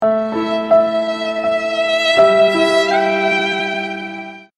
Sad Violin Sound Effect Free Download
Sad Violin